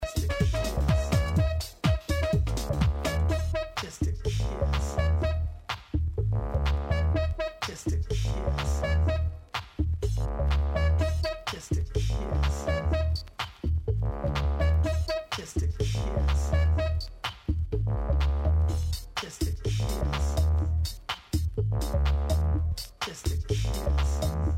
special interview zikenntsock.mp3